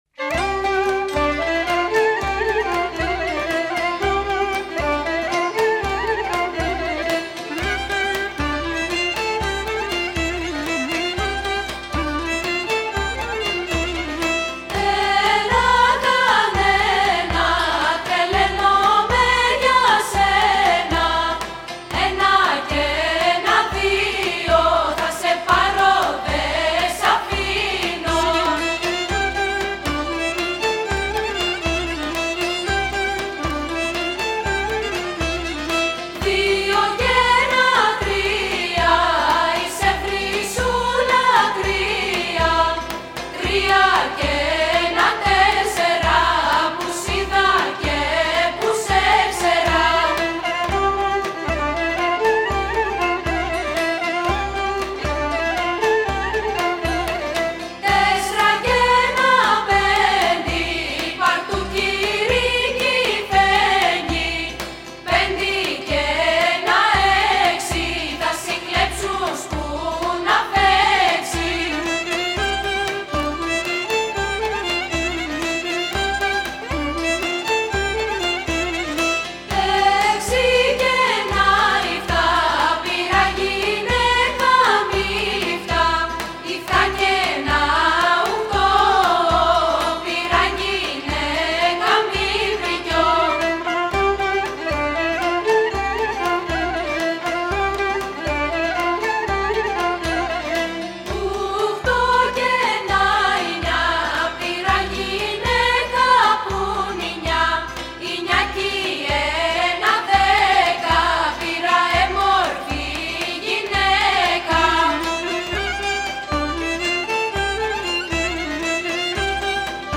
Τραγούδια των Αποκριών
Τα αποκριάτικα τραγούδια που ακολουθούν ερμηνεύει η ΧΟΡΩΔΙΑ ΑΙΓΑΙΟΥ του Κ.Α.Λ.Μ.Ε..